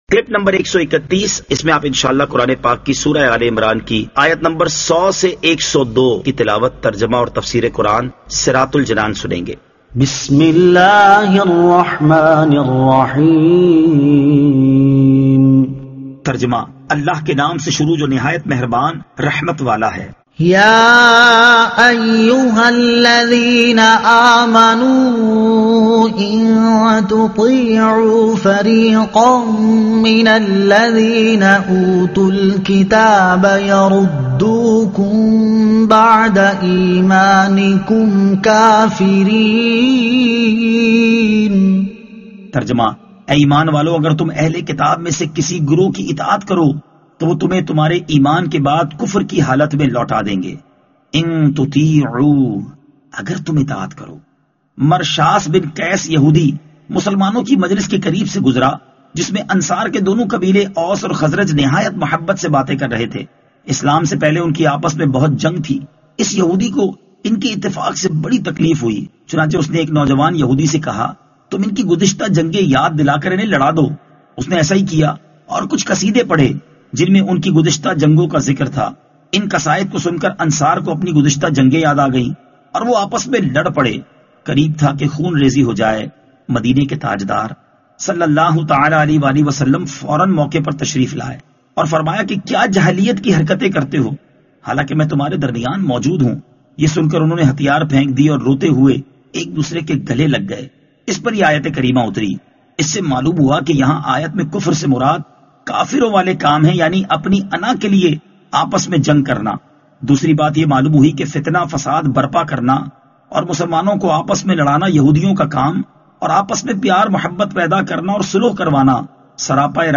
Surah Aal-e-Imran Ayat 100 To 102 Tilawat , Tarjuma , Tafseer